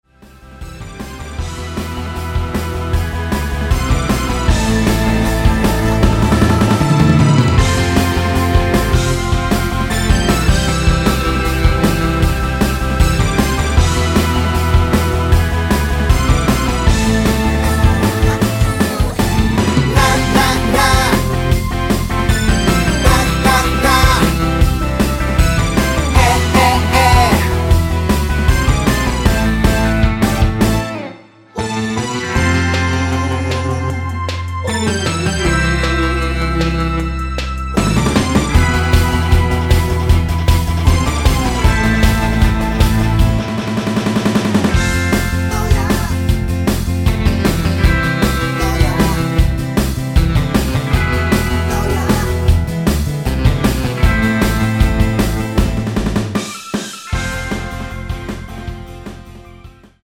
원키 코러스 포함된 MR 입니다.(미리듣기 참조)
Am
앞부분30초, 뒷부분30초씩 편집해서 올려 드리고 있습니다.